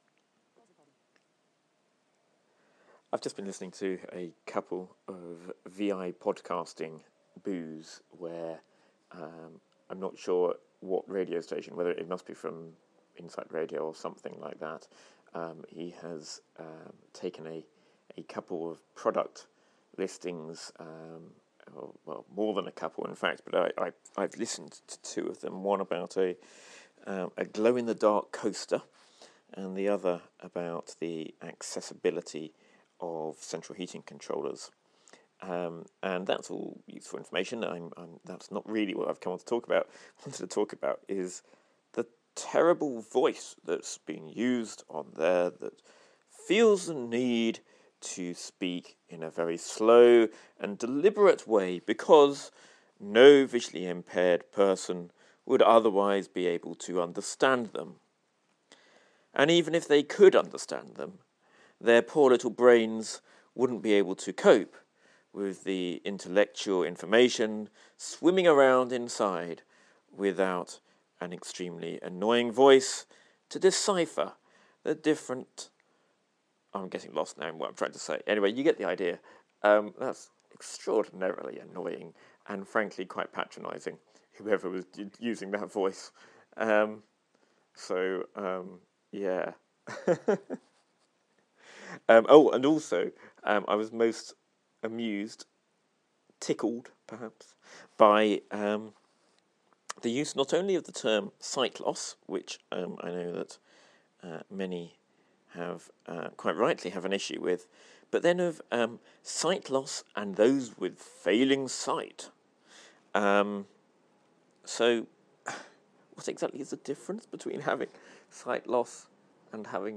Patronising speech or deliberate delivery - you decide